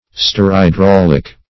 Sterhydraulic \Ster`hy*drau"lic\, a. [Stereo- + hydraulic.]